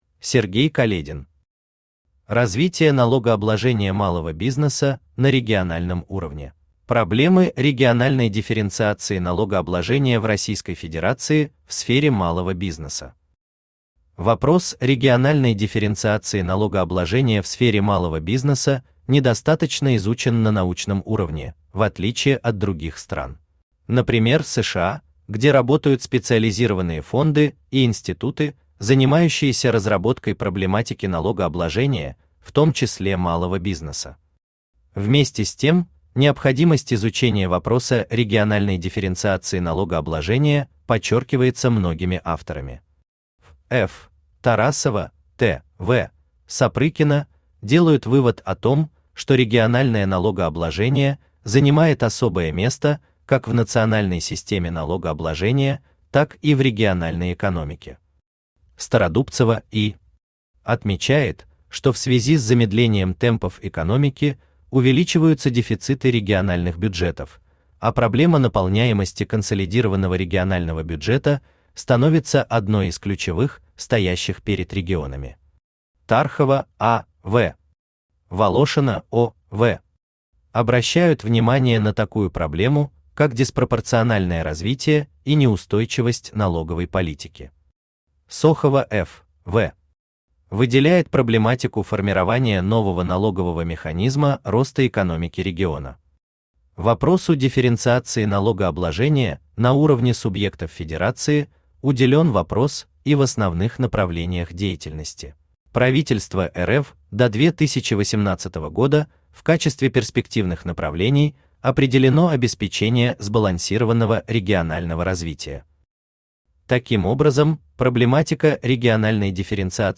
Aудиокнига Развитие налогообложения малого бизнеса на региональном уровне Автор Сергей Каледин Читает аудиокнигу Авточтец ЛитРес.